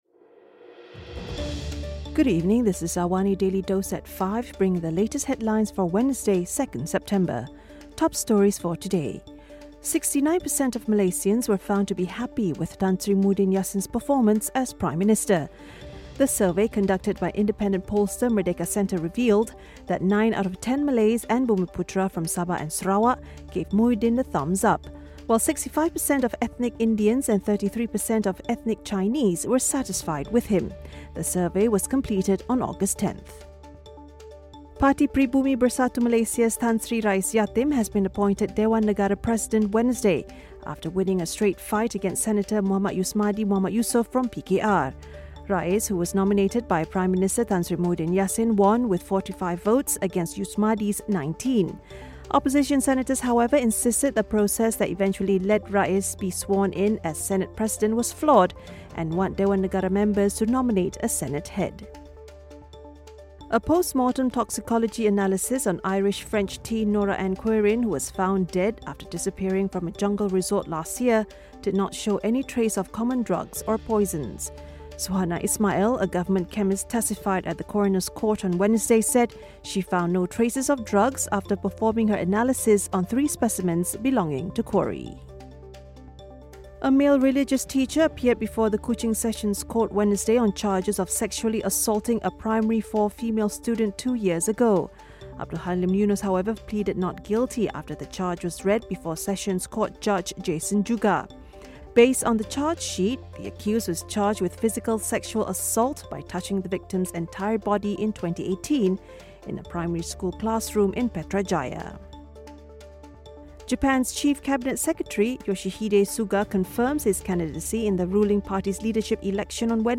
Also, Charlie Hebdo jihadist killings trial begins at Paris court. Listen to the top stories of the day, reporting from Astro AWANI newsroom — all in 3 minutes.